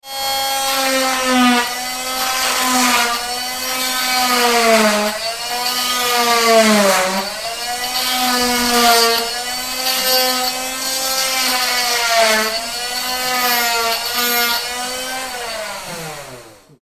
Catégorie: Bruitages